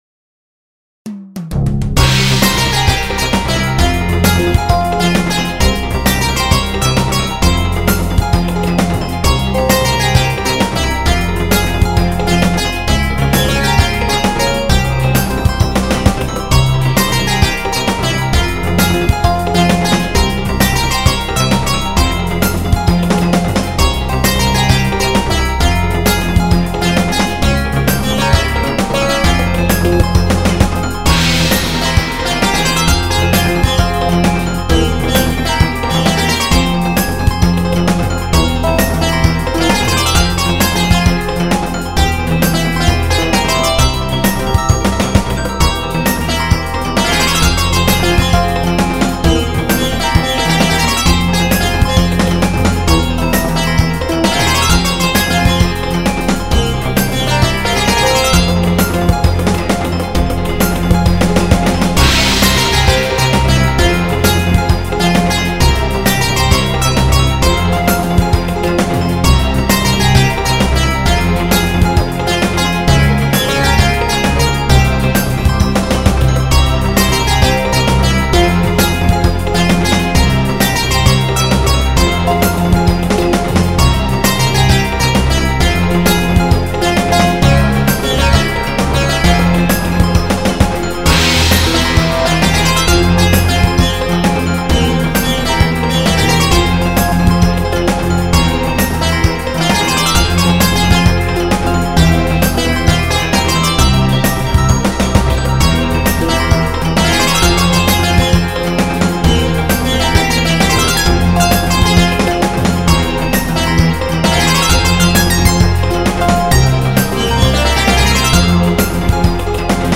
BGM
アップテンポロング民族